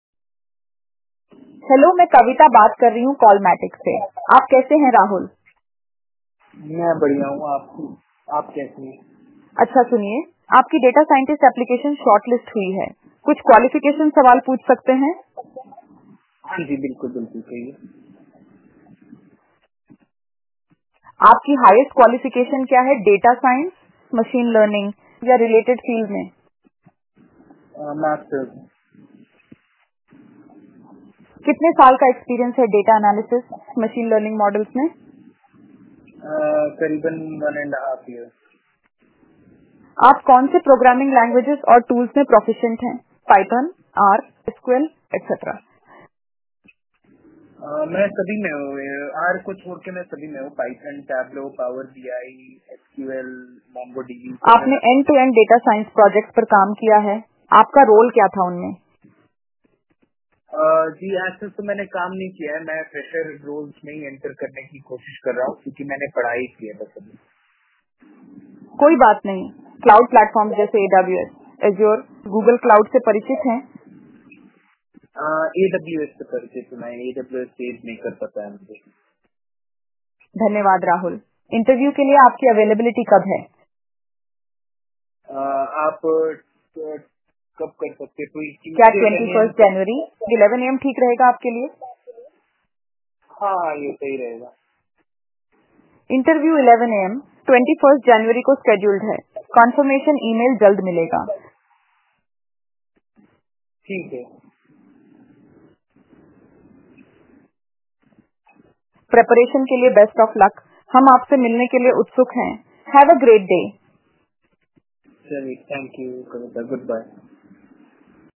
See our AI in action
Hiring_Hindi_website.wav